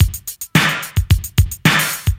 • 73 Bpm 80's Breakbeat Sample F# Key.wav
Free drum groove - kick tuned to the F# note. Loudest frequency: 2224Hz
73-bpm-80s-breakbeat-sample-f-sharp-key-dve.wav